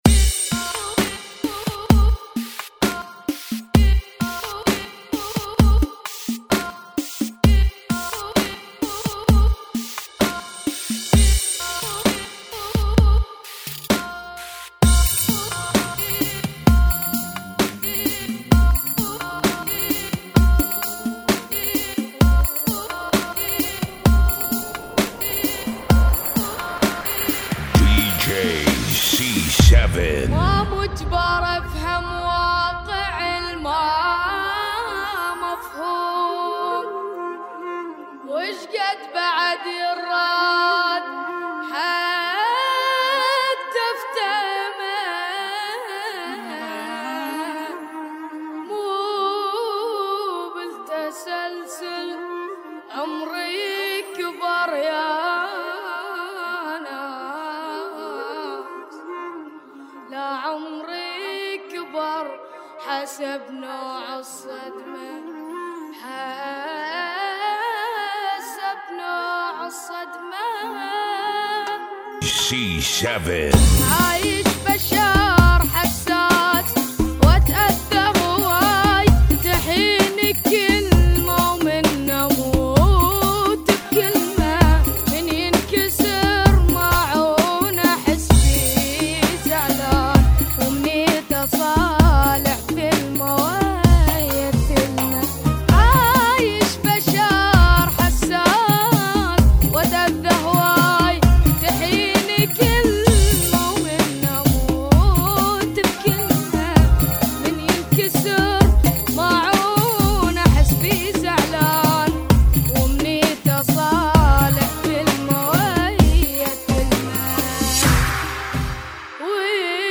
BPM 65